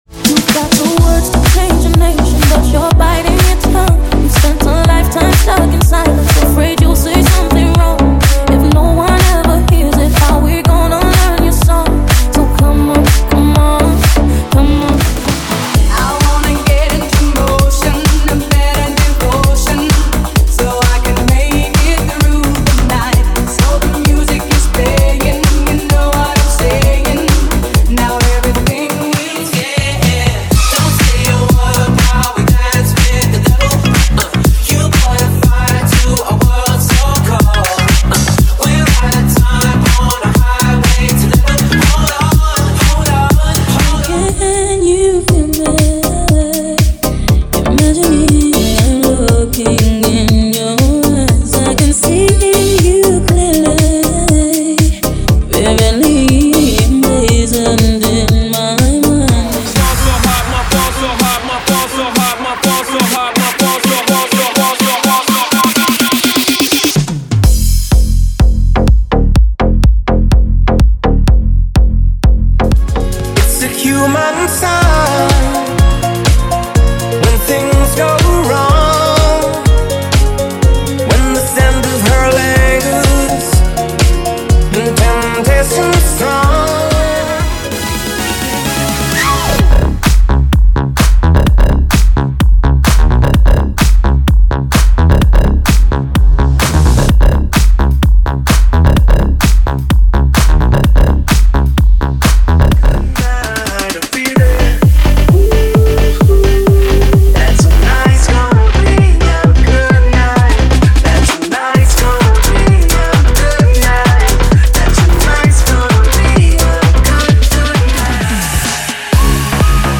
Deep House: 100 Músicas
– Sem Vinhetas